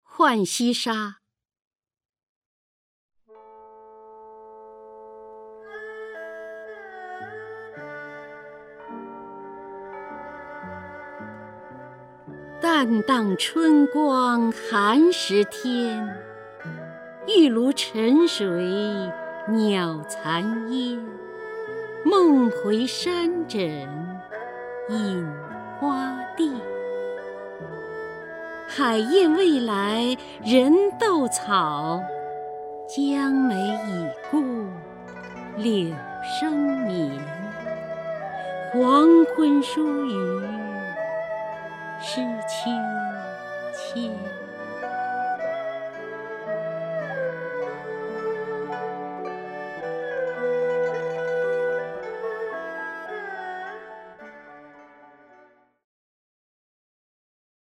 首页 视听 名家朗诵欣赏 姚锡娟
姚锡娟朗诵：《浣溪沙·淡荡春光寒食天》(（南宋）李清照)